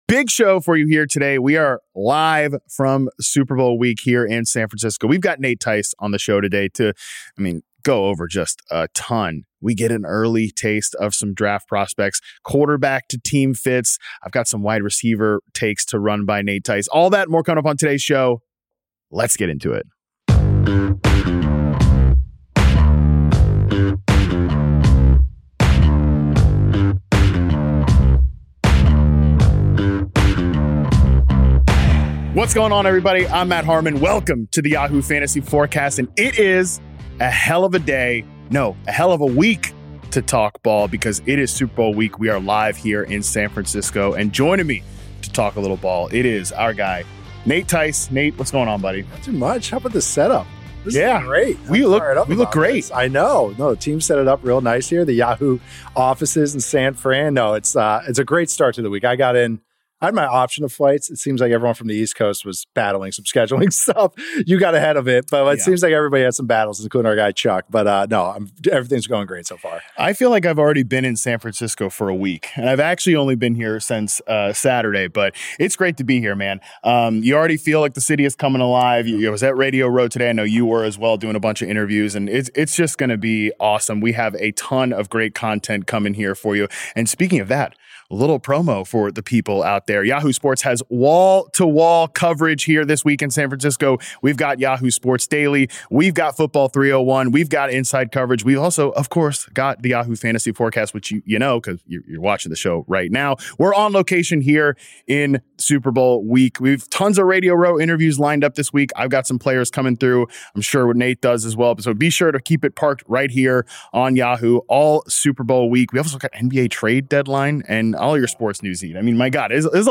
LIVE from Super Bowl LX: Lessons teams can learn from Seahawks, Patriots + Mock Draft 1.0